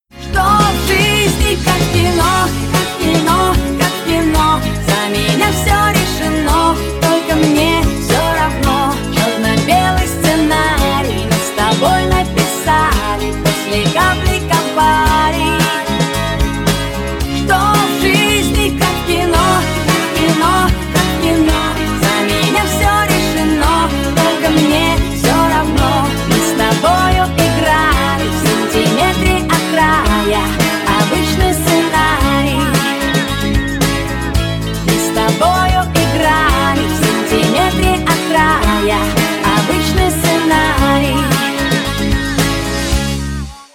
• Качество: 320, Stereo
эстрадные